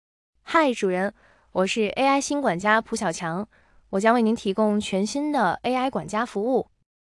TTS语音合成
这一代 TTS 不再是“机器发声”，而是可以真正传递情感和温度的 AI 声音，在自然度、韵律、口气、情绪、语气词表达等方面全面突破，让听者几乎无法分辨“人声”与“机器声”。